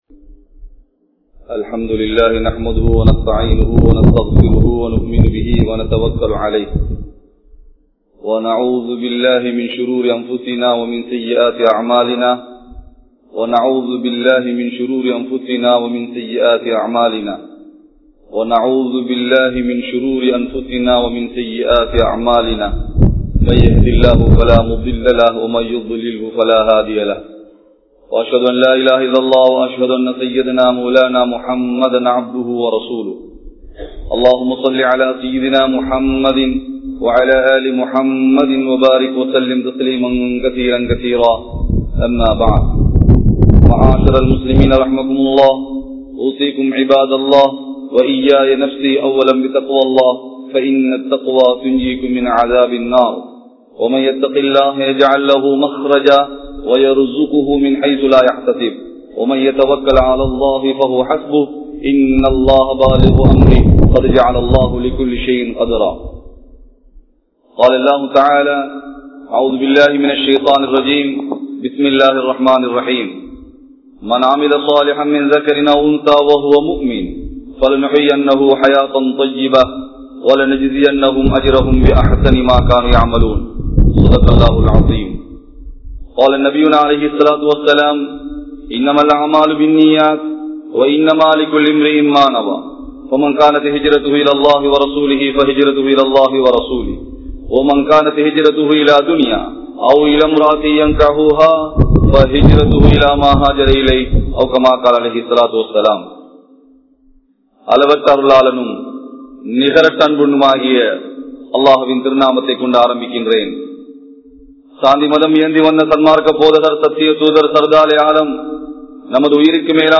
Nabi(SAW)Avarhalin Valihaattal (நபி(ஸல்)அவர்களின் வழிகாட்டல்) | Audio Bayans | All Ceylon Muslim Youth Community | Addalaichenai